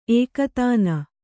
♪ ēkatāna